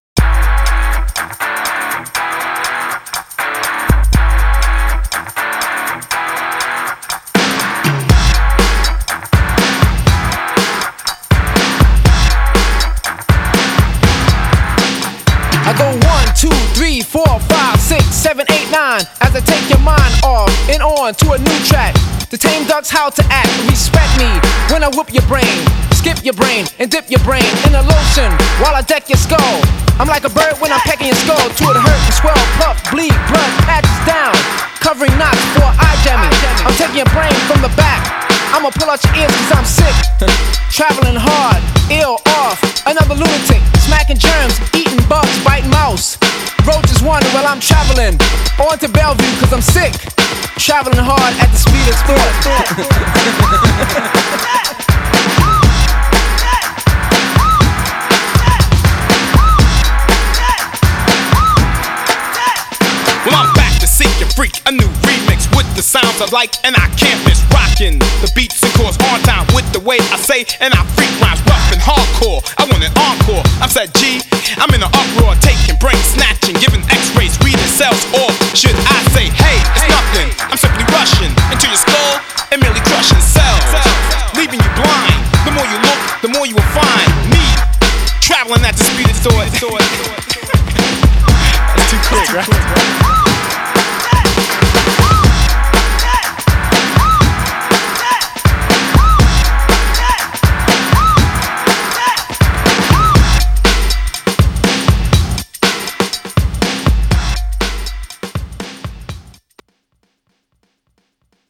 Punchy old school beats